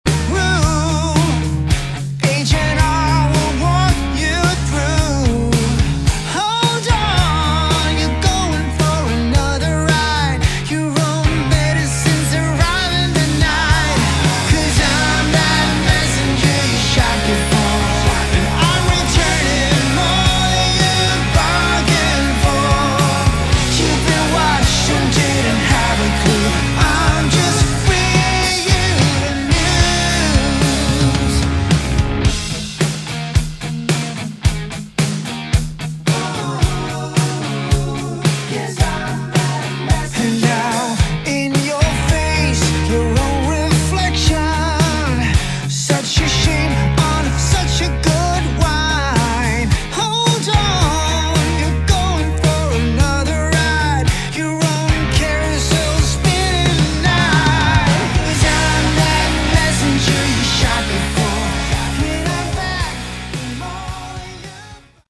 Category: Melodic Hard Rock
guitars
bass, vocals
drums